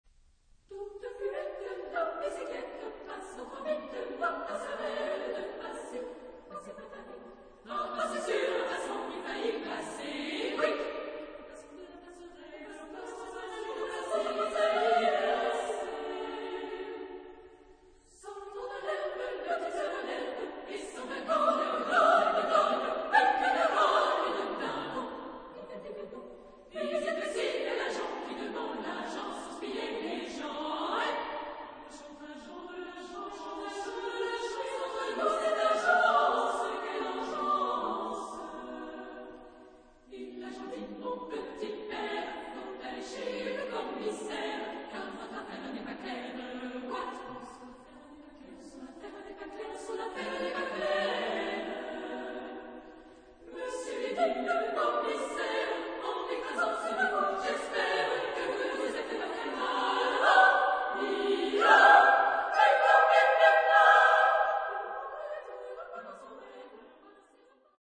Genre-Stil-Form: zeitgenössisch ; Liedsatz ; weltlich
Charakter des Stückes: humorvoll
Chorgattung: SSA  (3 Kinderchor ODER Frauenchor Stimmen )
Tonart(en): G-Dur